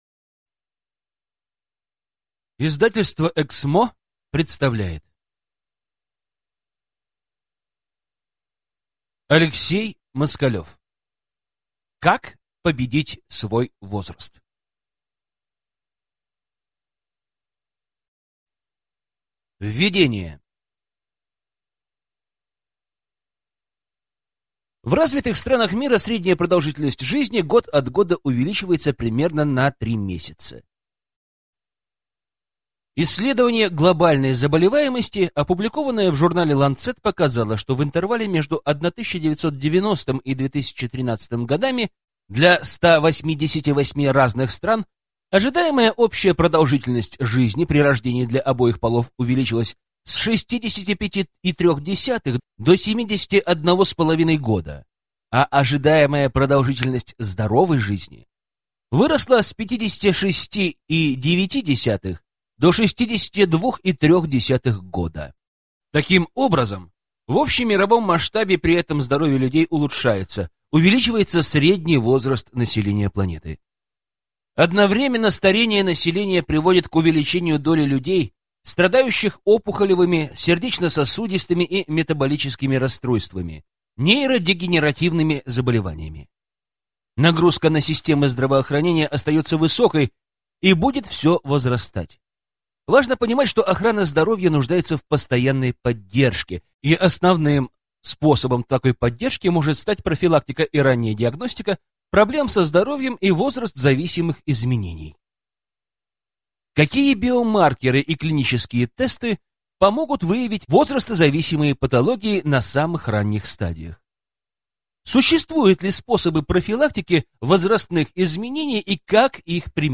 Аудиокнига Как победить свой возраст? Восемь уникальных способов, которые помогут достичь долголетия | Библиотека аудиокниг